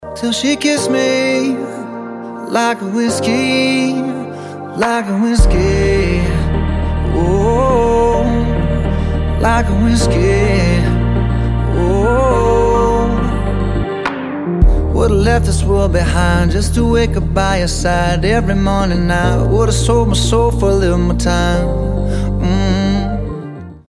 • Качество: 320, Stereo
поп
мужской вокал
спокойные
лиричные